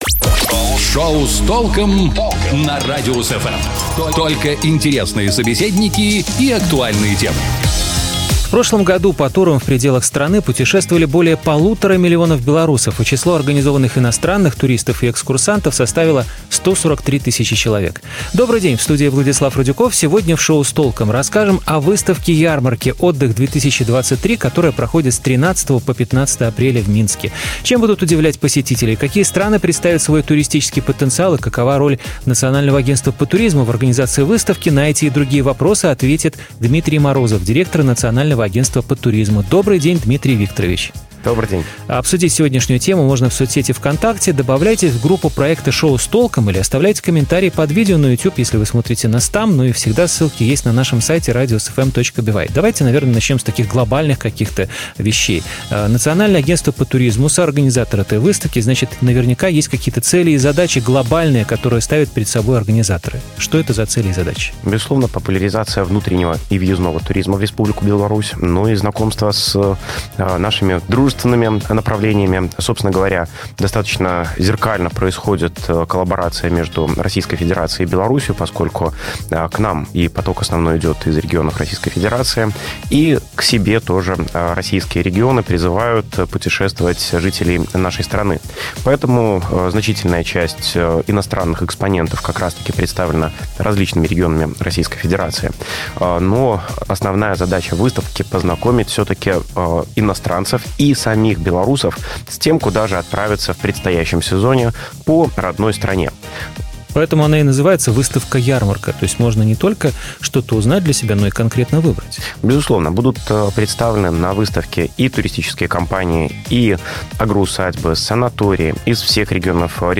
На эти и другие вопросы ответит Дмитрий Морозов, директор Национального агентства по туризму.